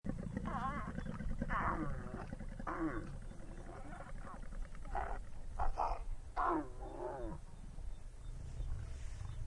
Lion-accoupl Sound Effect Download: Instant Soundboard Button
Lion Sounds607 views